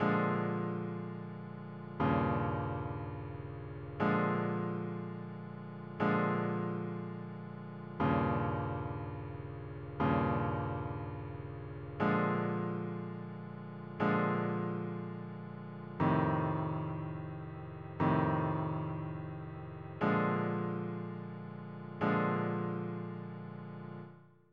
blues changes chords
This is the progression used above, just for comparison’s sake.